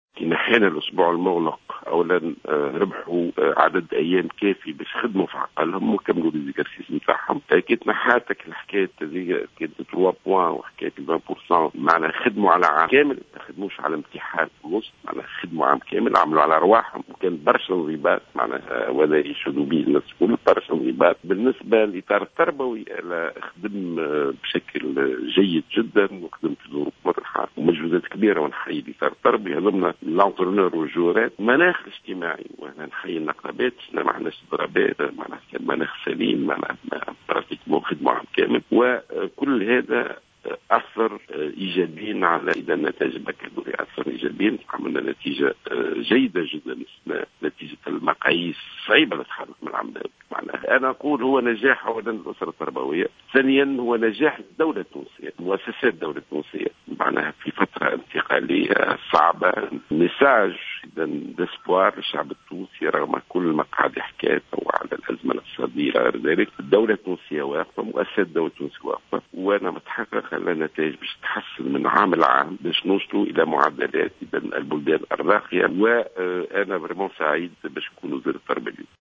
وفي تصريح خصّ به "الجوهرة أف أم" اليوم الجمعة اعتبر جلول أنّ نجاح الدورة الرئيسة لباكالوريا 2016 هو نجاح للأسرة التربوية وللدولة التونسية و وزارة التربية كإحدى مؤسسات الدولة خاصة في الفترة الانتقالية الصعبة والأزمة الاقتصادية التي تمر بها البلاد مضيفا أنّ هذا النجاح يُعدّ رسالة أمل للشعب التونسي في ظل هذه الظروف.